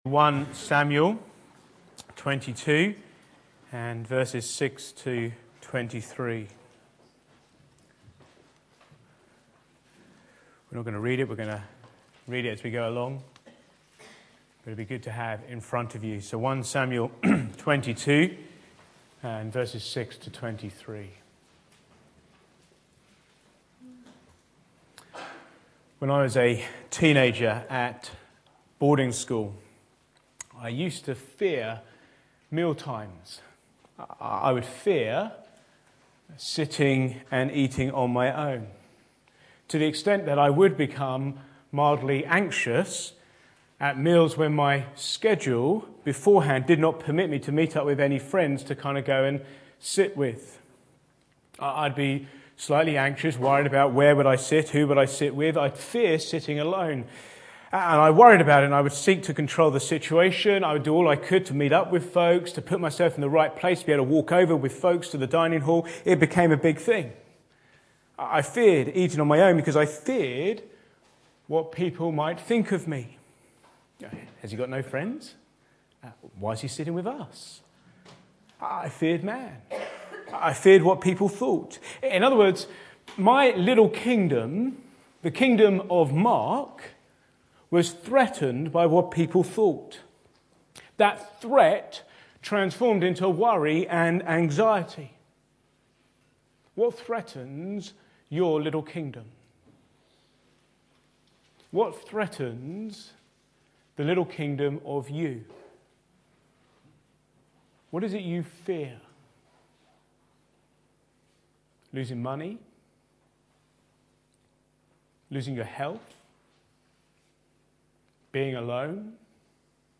Back to Sermons A murderous heart